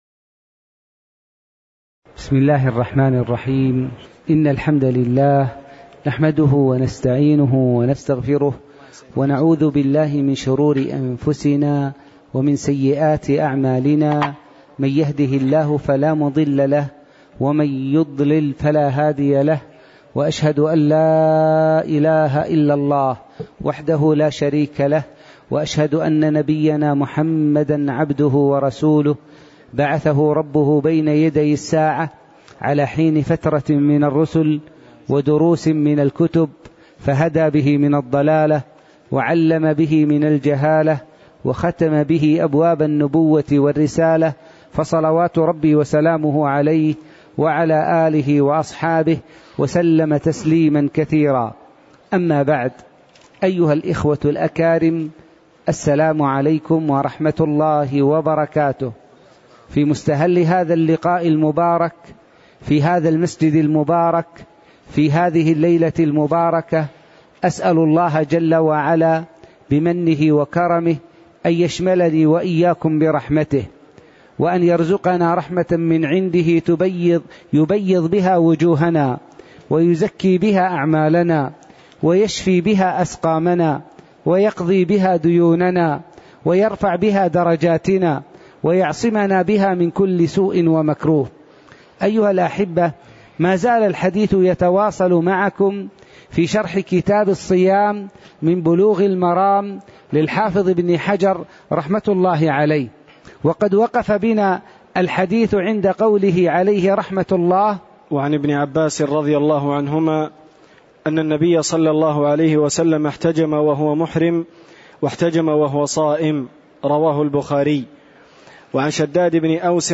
تاريخ النشر ٢٦ شعبان ١٤٣٧ هـ المكان: المسجد النبوي الشيخ